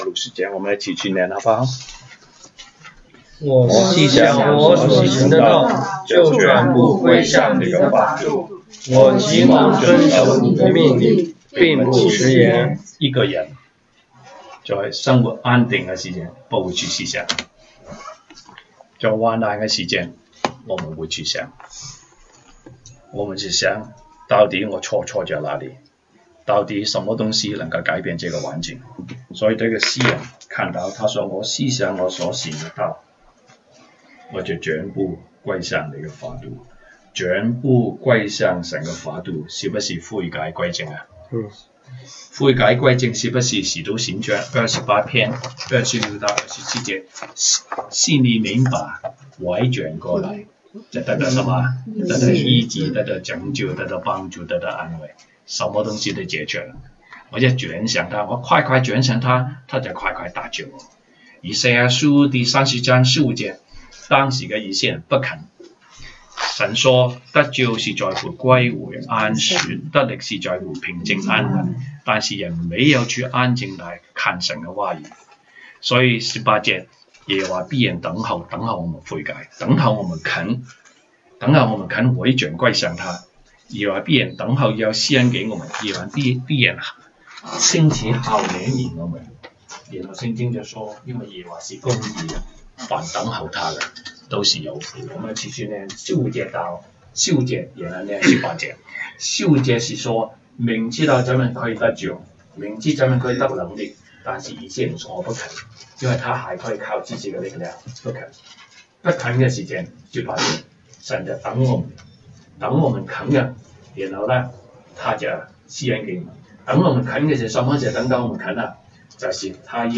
週一國語研經 Monday Bible Study « 東北堂證道